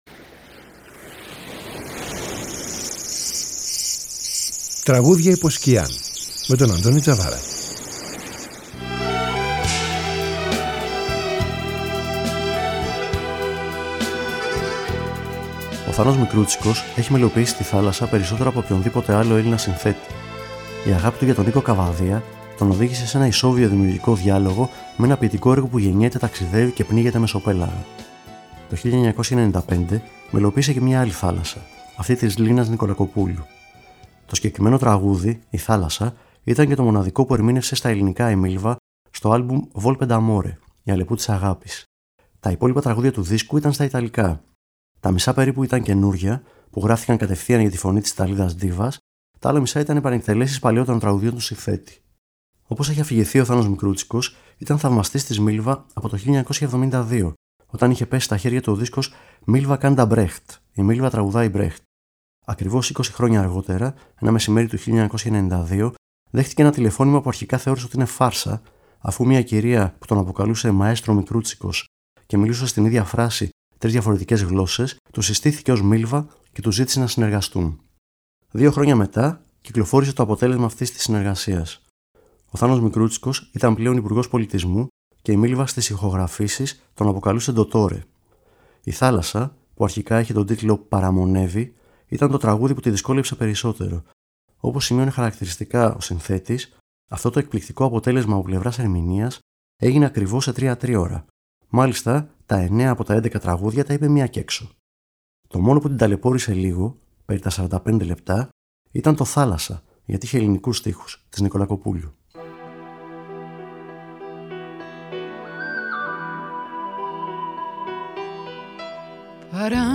Το συγκεκριμένο τραγούδι -Η Θάλασσα- ήταν το μοναδικό που ερμήνευσε στα ελληνικά η Μίλβα, στο άλμπουμ Volpe D’ Amore, Η Αλεπού της Αγάπης.